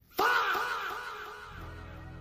Sound Effects